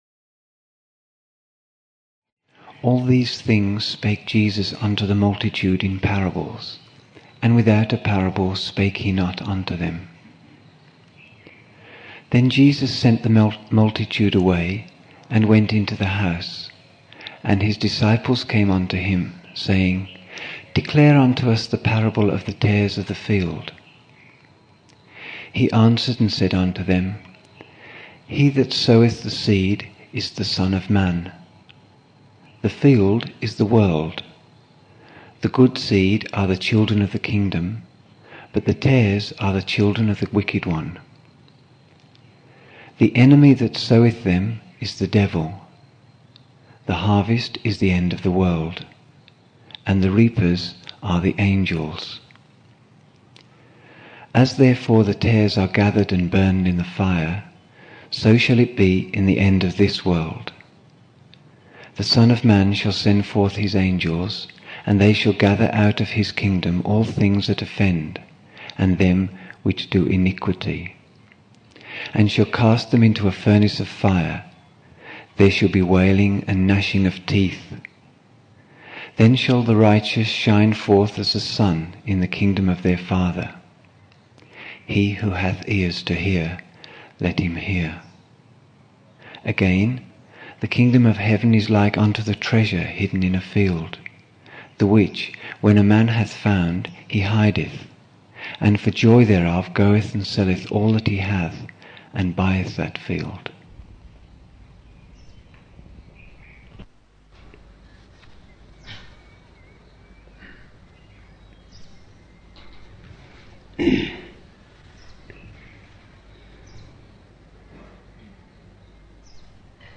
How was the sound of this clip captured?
8 November 1975 morning in Buddha Hall, Poona, India